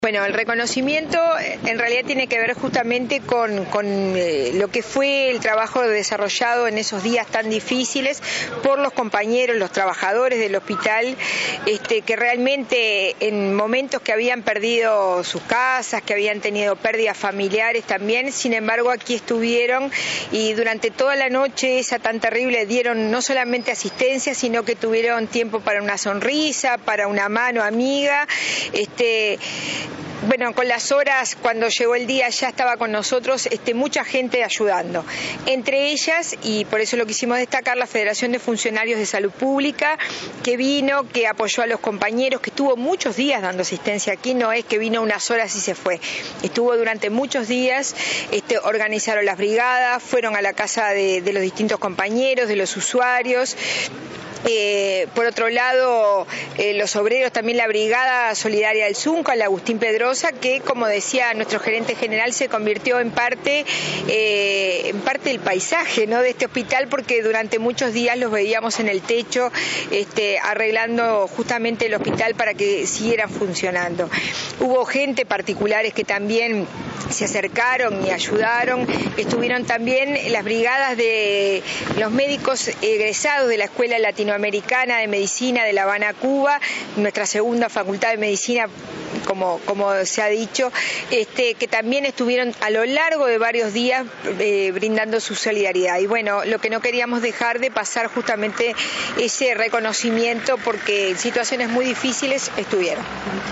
“Dolores tiene una fuerza que transmitió a todo el Uruguay. Son un orgullo para el país”, aseguró la titular de ASSE, Susana Muñiz, durante el reconocimiento a la labor del personal de salud del hospital local, así como a la Federación de Funcionarios de Salud Pública y a la brigada del sindicato de la construcción, que se acercaron desde el primer día posterior al tornado para ayudar en la reconstrucción.